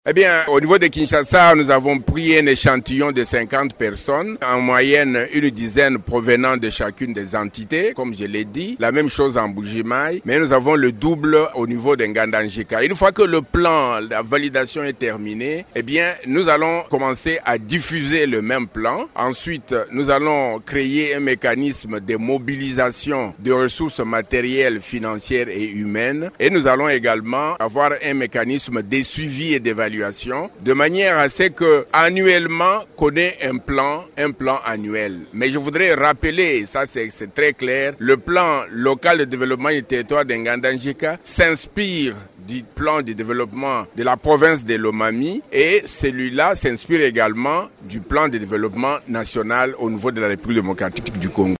« Nous allons créer et mobiliser des ressources matérielles, financières et humaines et on mettra en place un mécanisme de suivi et d’évaluation », a promis Bruno Miteo, chef de la maison civile du chef de l’état et PCA de Lunkonko, porteur du projet.